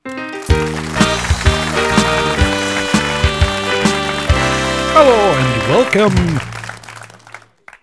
Wav World is the home of comedy desktop sounds.
cheesy sign offCheesy Talkshow Welcome sound
talkshow.wav